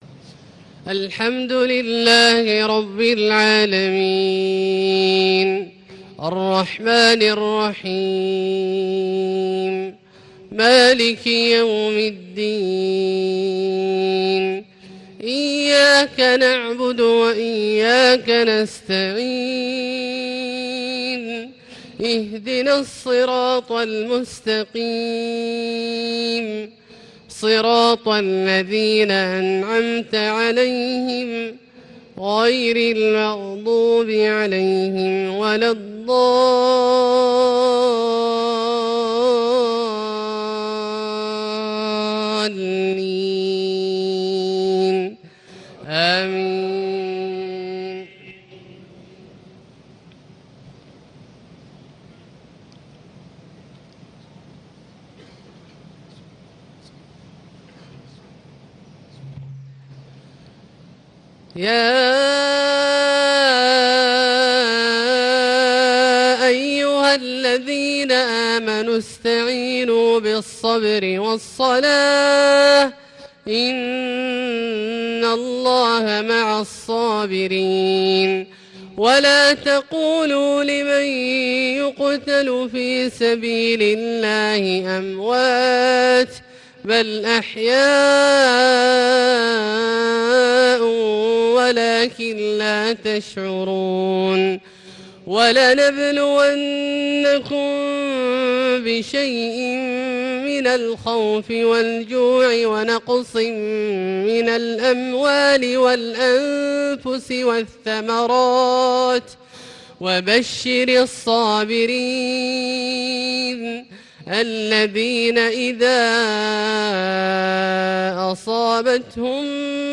صلاة الفجر 13 ذو الحجة 1437هـ من سورتي البقرة 153-158 و النور 62-64 > 1437 🕋 > الفروض - تلاوات الحرمين